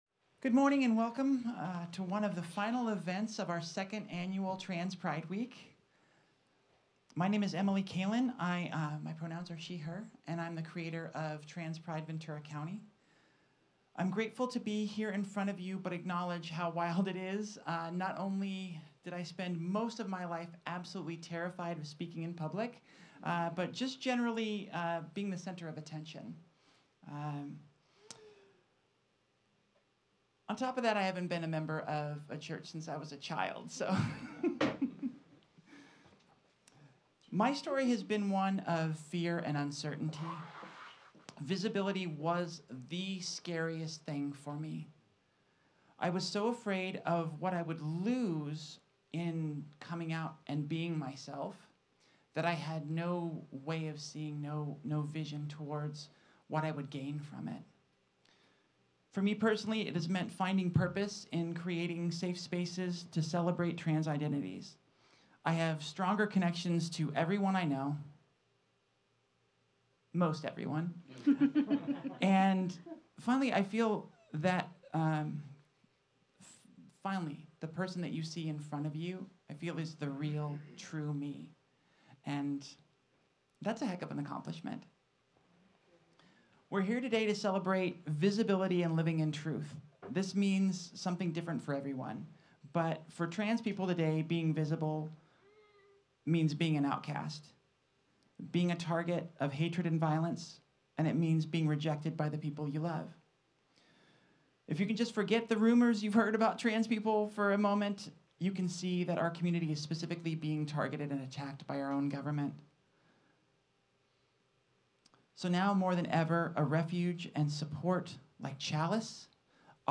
Join us for a very special service dedicated to celebrating and uplifting our transgender community during this Week of Trans Visibility. Together, we’ll honor the courage of those who live their truth and advocate for a world where everyone can be seen and valued for who they are.